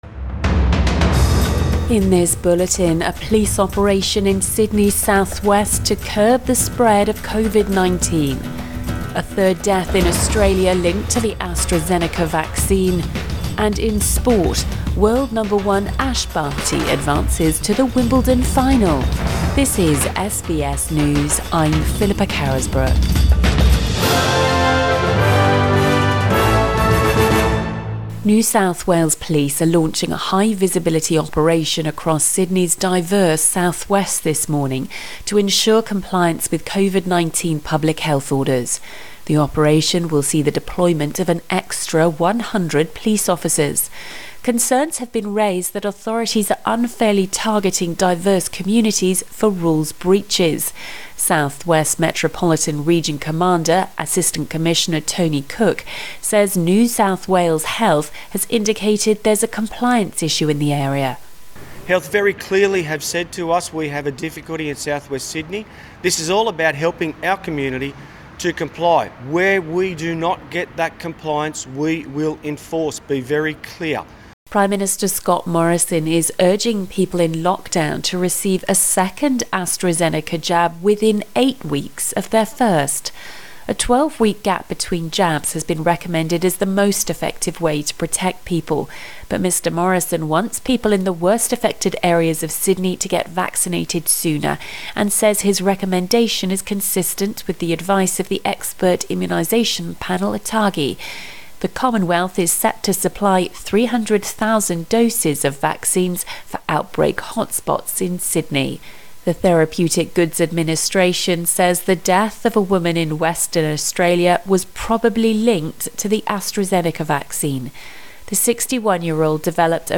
AM bulletin 9 July 2021